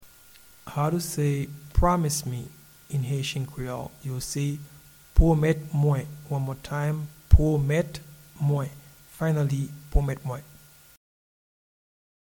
Promise-me-in-Haitian-Creole-Pwomet-mwen.mp3